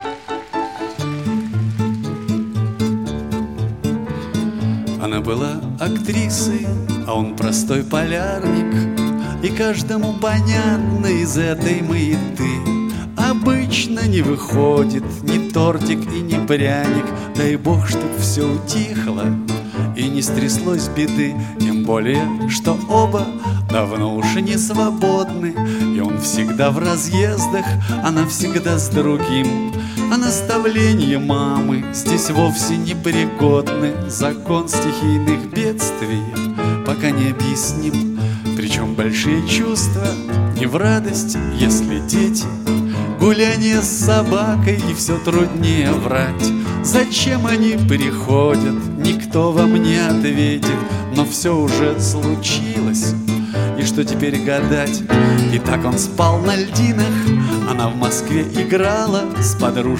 Pop
записанный во время концертов в Кремле.